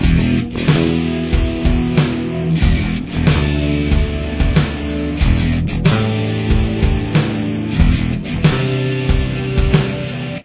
Intro/Verse
Here’s what the guitar and bass sound like together: